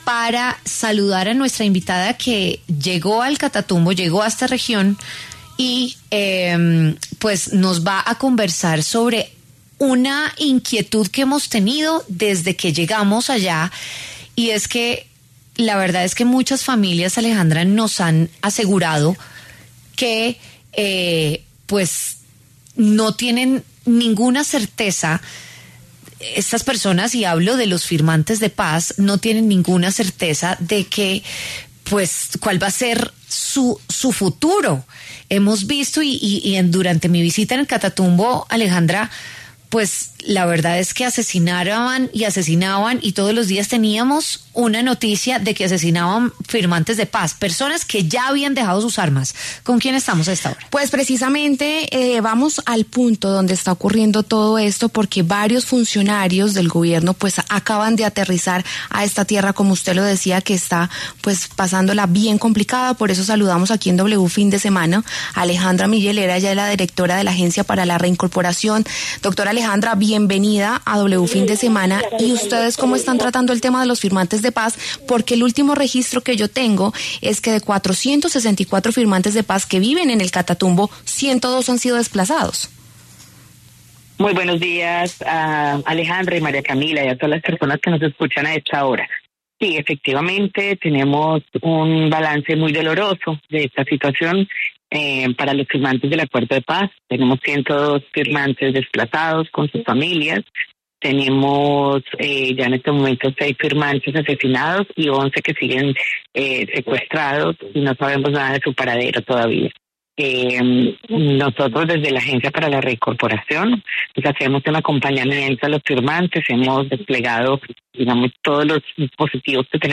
Alejandra Miller, directora de la Agencia de Reincorporación, explicó en W Fin De Semana que el panorama para los firmantes de paz en el Catatumbo es “muy doloroso”.